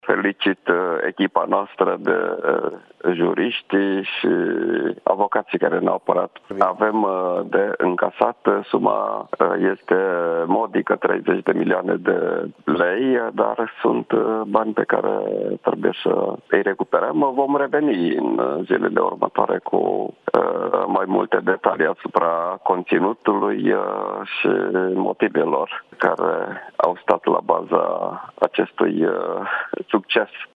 Minstrul Marcel Boloș, într-o declarație la Digi 24, după comunicarea verdictului